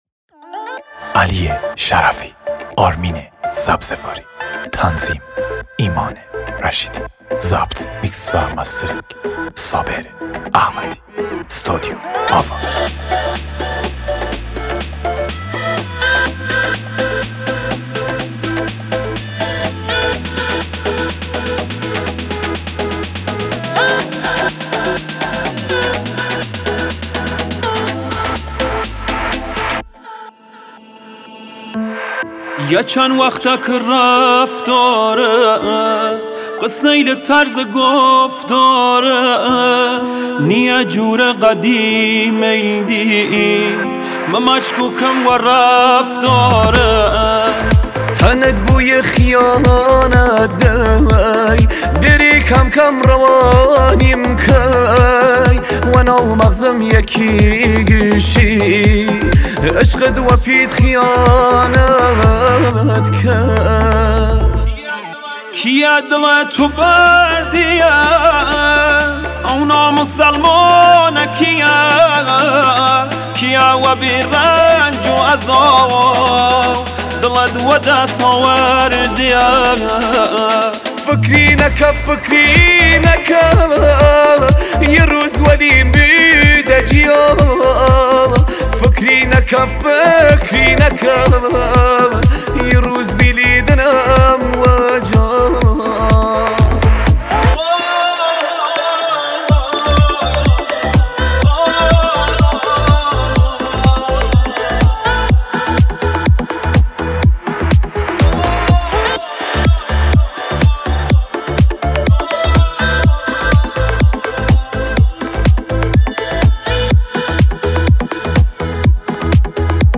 آهنگ کردی و سنندجی
Mahalli